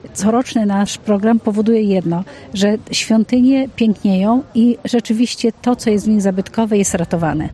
Członkini zarządu dodaje, że ten program daje efekty: